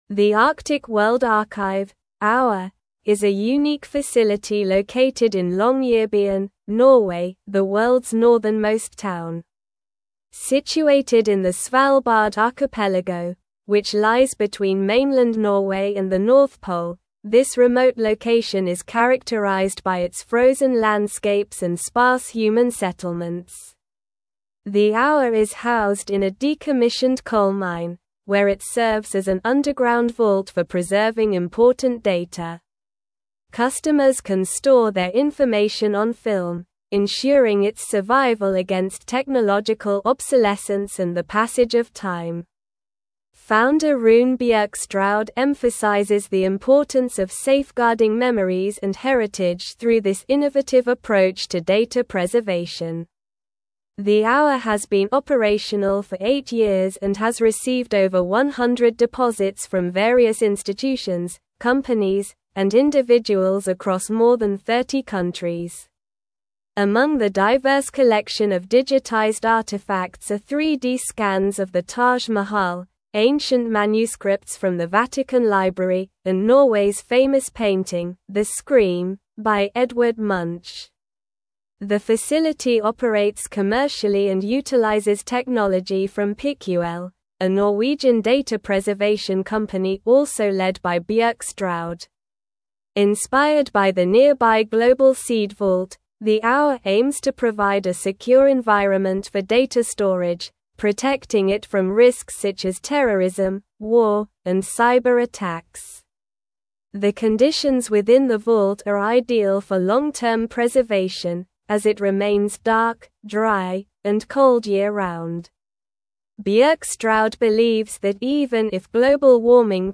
Slow
English-Newsroom-Advanced-SLOW-Reading-Preserving-the-Future-at-The-Arctic-World-Archive.mp3